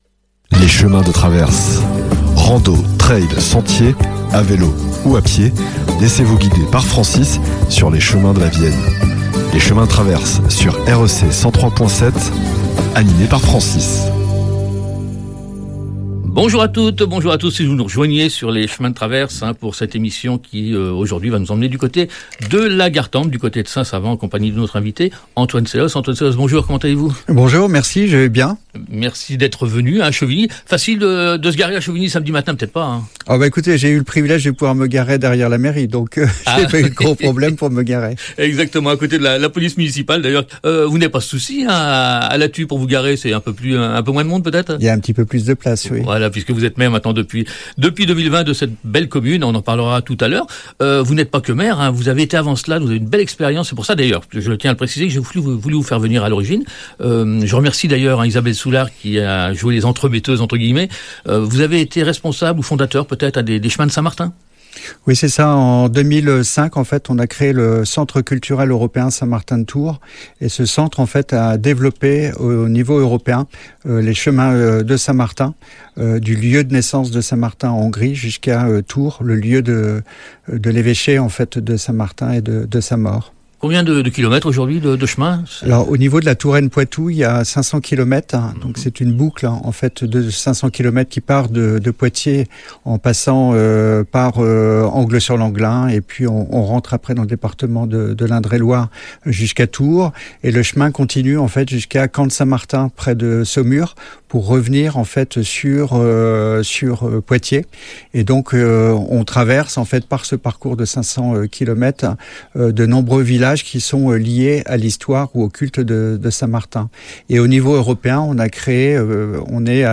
recevait Antoine Selosse – Maire de Lathus St Remy